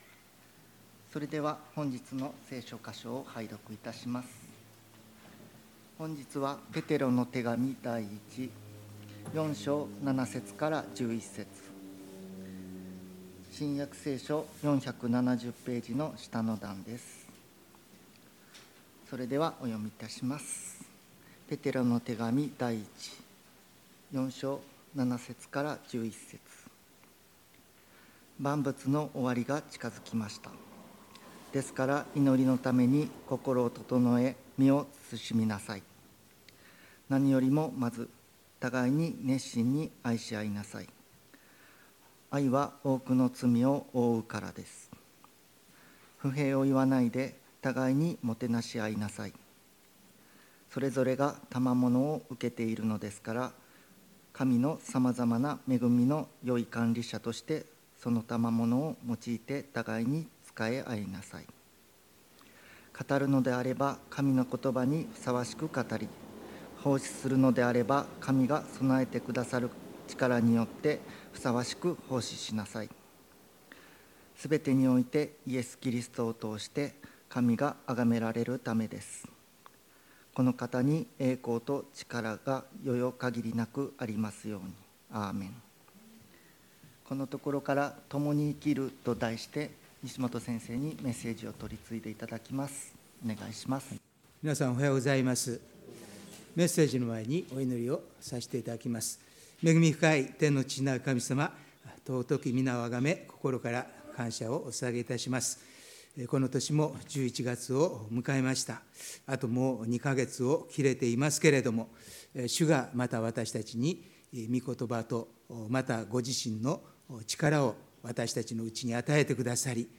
礼拝メッセージ「共に生きる」│日本イエス・キリスト教団 柏 原 教 会